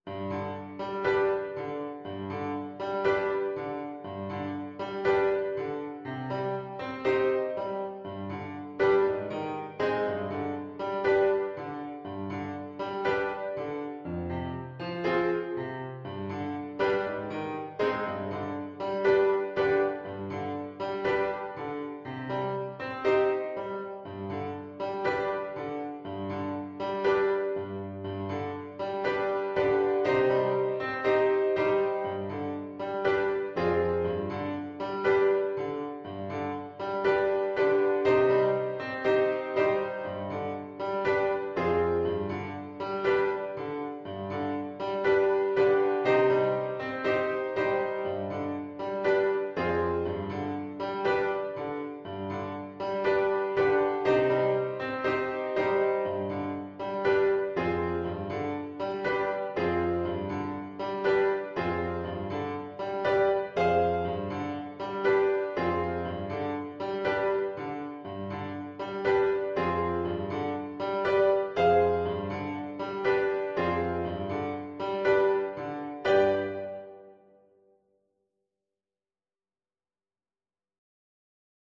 Play (or use space bar on your keyboard) Pause Music Playalong - Piano Accompaniment Playalong Band Accompaniment not yet available transpose reset tempo print settings full screen
4/4 (View more 4/4 Music)
Allegro moderato =120 (View more music marked Allegro)
C minor (Sounding Pitch) D minor (Clarinet in Bb) (View more C minor Music for Clarinet )